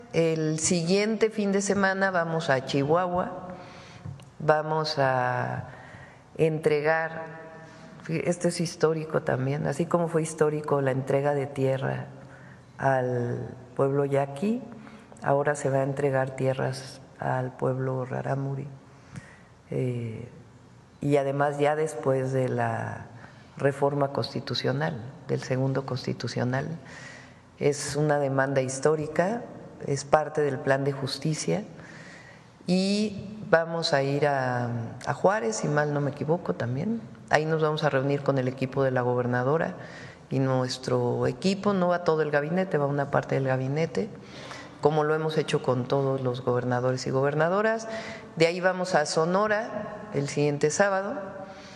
Durante la conferencia de prensa matutina de este miércoles, Sheinbaum Pardo brindó más información sobre su paso por Chihuahua, agendada para los días sábado 21 y domingo 22 de diciembre.